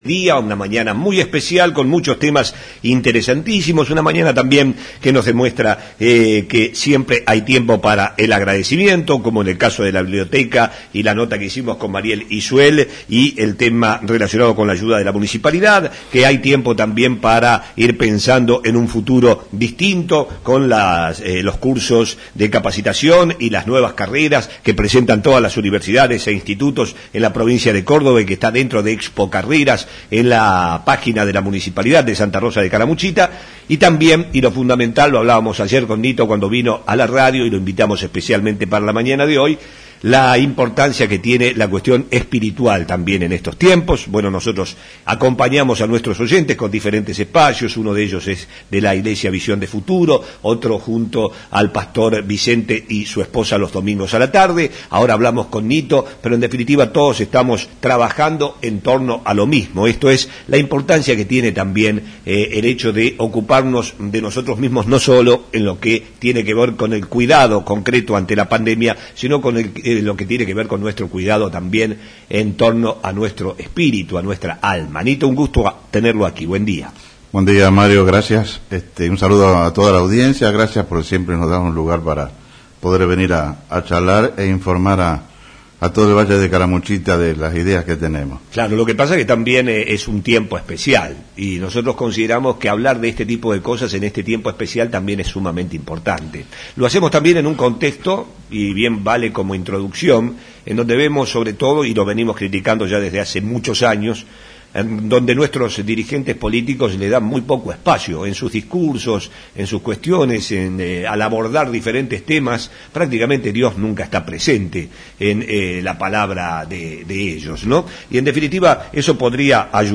visitó nuestros estudios